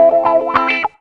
GTR 26 AM.wav